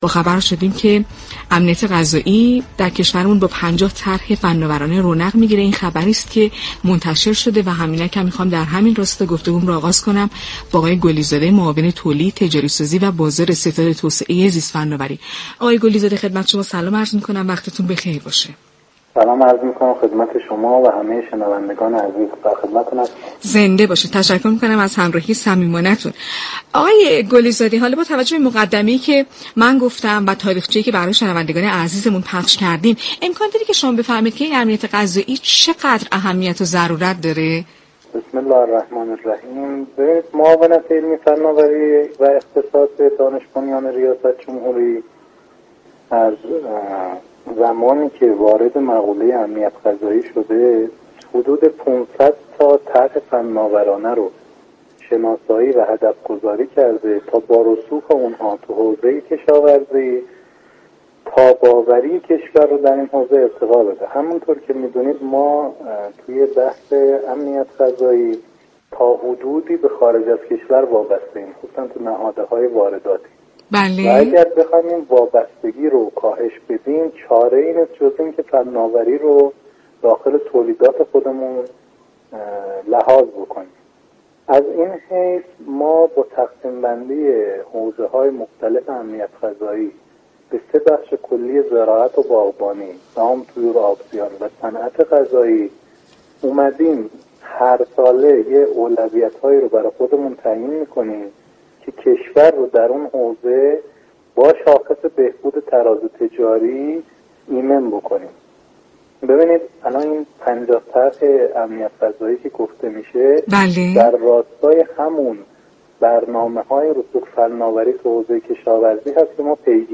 در گفت و گو با «علم بهتر است»